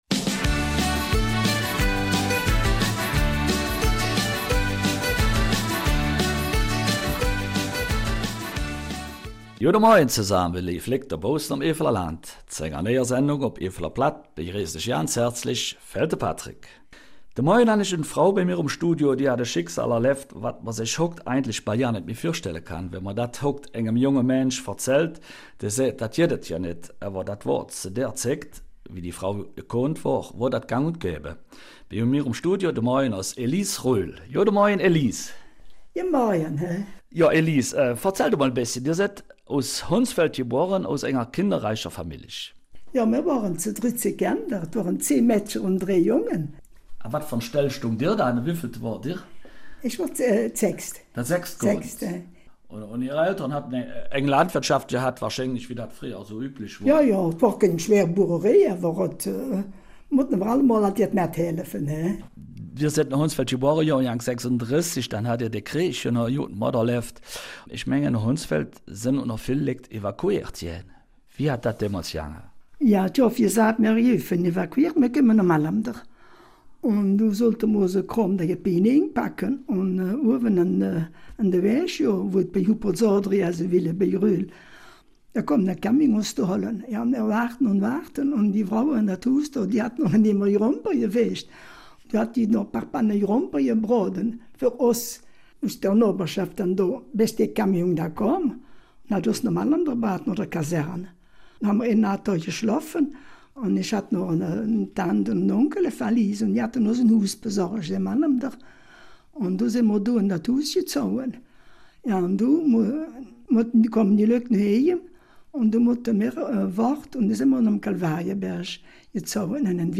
Eifeler Mundart - 21. September